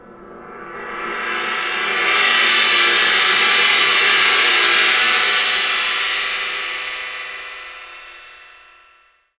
Index of /90_sSampleCDs/Roland LCDP03 Orchestral Perc/CYM_Gongs/CYM_Dragon Cymbl